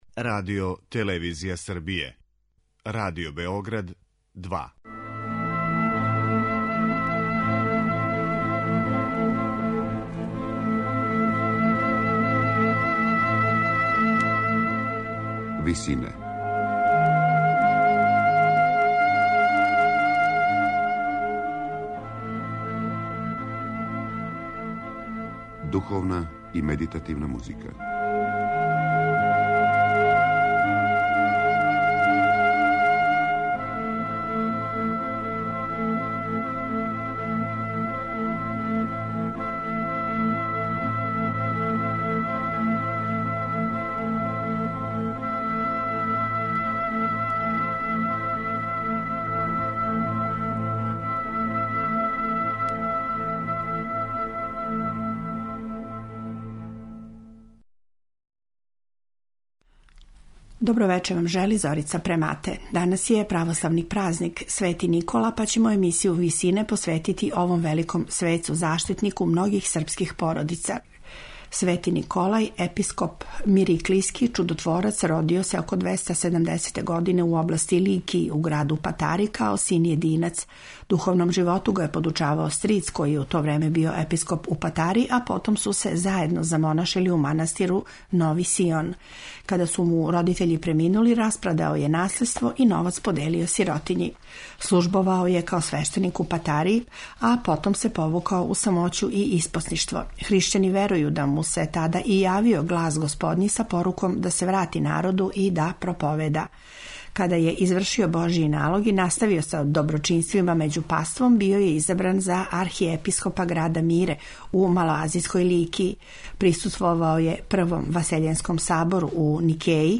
Емитоваћемо традиционални напев „Акатист Светом Николи'.
у ВИСИНАМА представљамо медитативне и духовне композиције аутора свих конфесија и епоха.
Традиционални напев „Акатист Светом Николи" емитоваћемо у интерпретацији чланова вокалног ансамбла „Величаније", из руског манастира „Св.